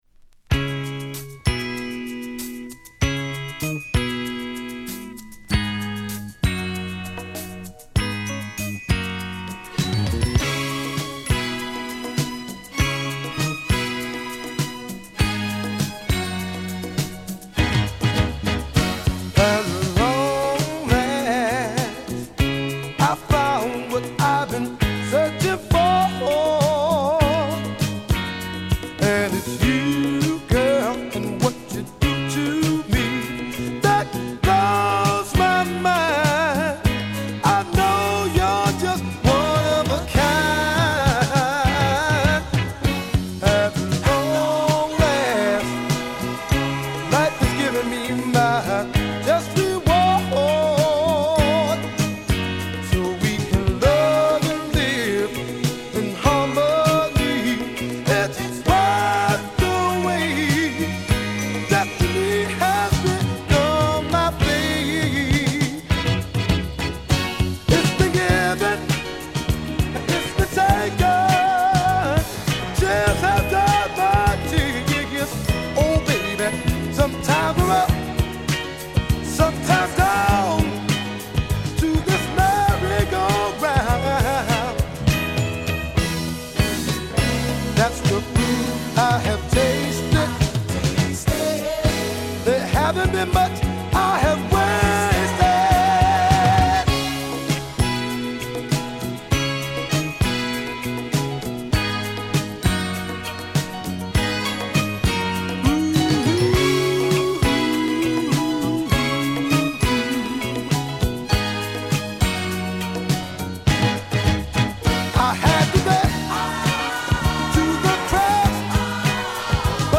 GARAGE CLASSIC
ディスコトラック